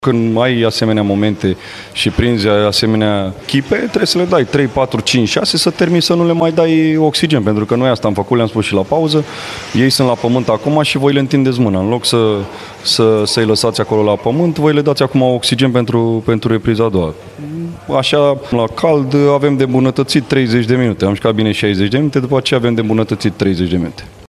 ”Principalul” Craiovei, Mirel Rădoi, povestit cum și-a avertizat jucătorii să nu se deconcentreze, în ciuda avantajului ce părea confortabil: